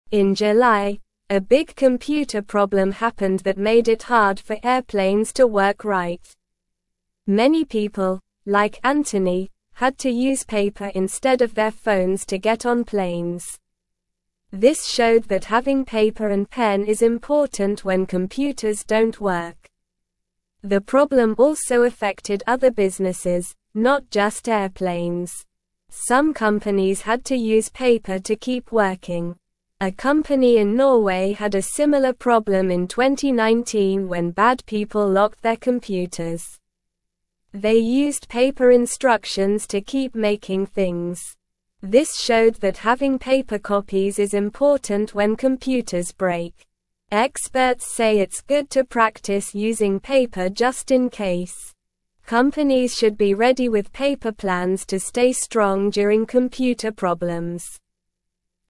Slow
English-Newsroom-Beginner-SLOW-Reading-Paper-is-important-when-computers-dont-work.mp3